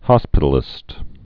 (hŏspĭt-l-ĭst)